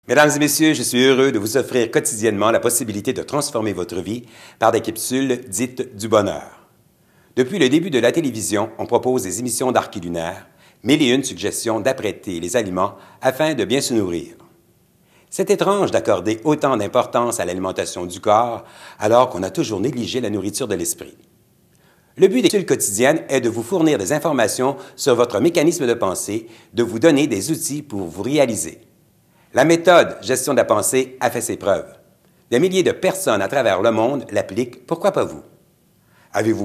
De petites capsules audio inspirantes, motivantes et réconfortantes.